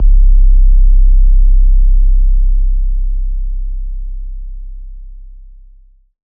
808 SOUTHSIDE.wav